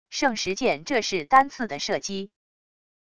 圣石箭这是单次的射击wav音频